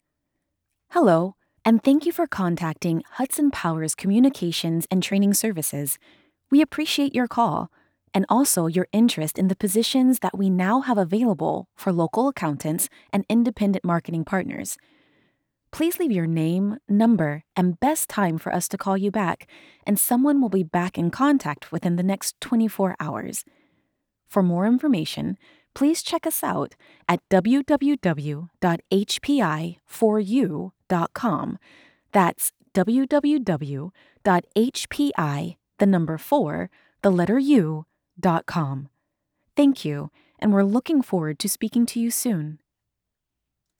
Authentic, friendly, female voice talent with quick turnaround and superb customer service
Phone Messaging/IVR